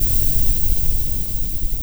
RI_RhythNoise_130-04.wav